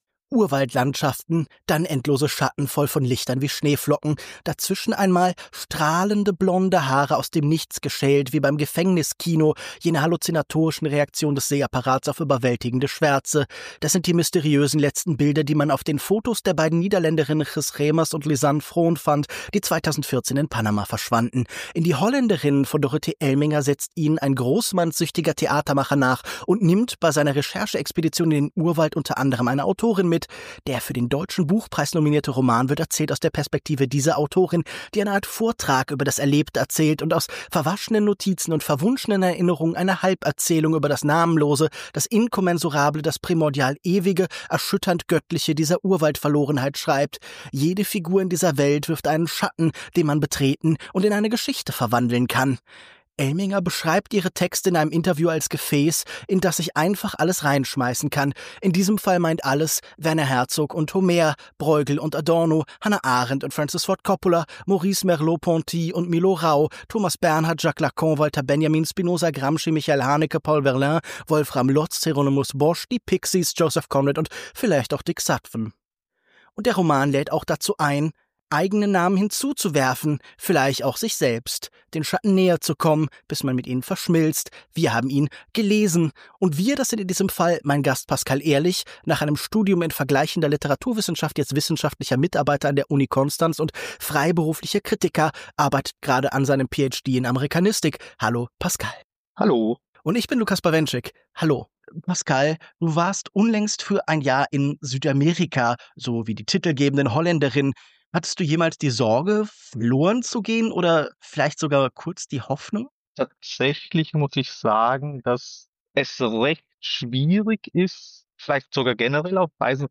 Achtung: Die Sound-Qualität ist durch einen technischen Fehler in dieser Folge nicht optimal.